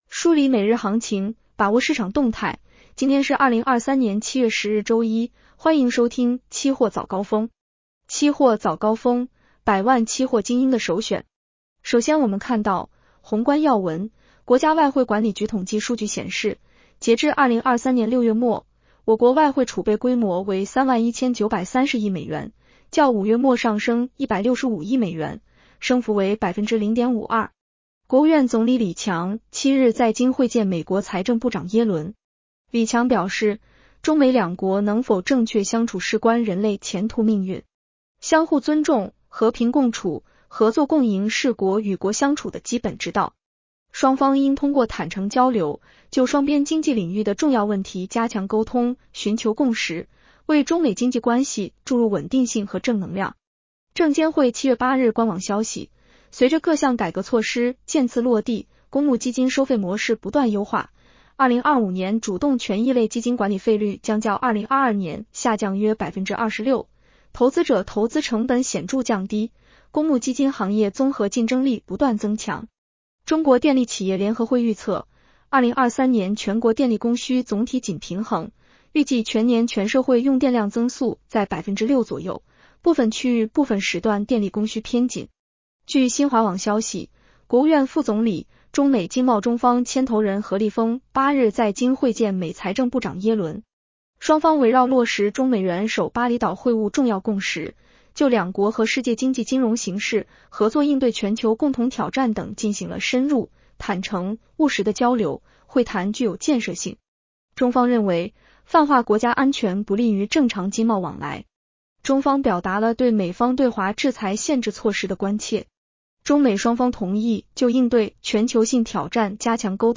【期货早高峰-音频版】 女声普通话版 下载mp3 宏观要闻 1.